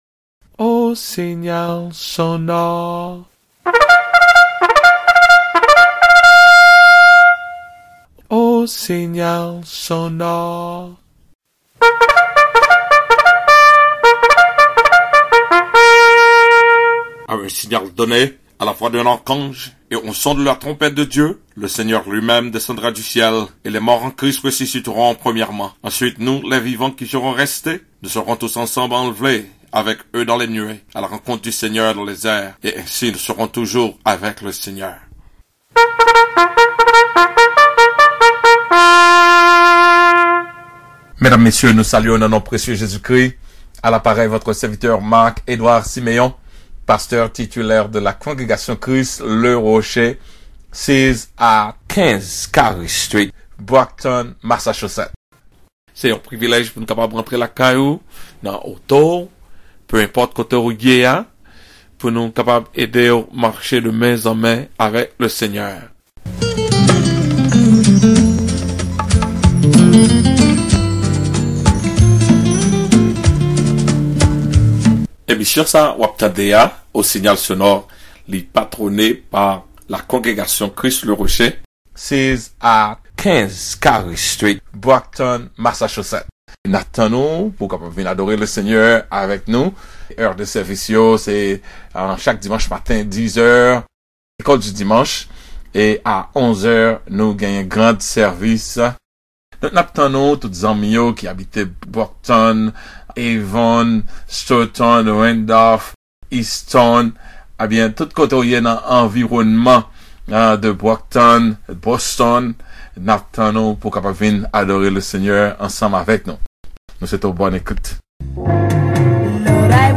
CLICK HERE TO DOWNLOAD THE SERMON: FATHERHOOD OF GOD sermon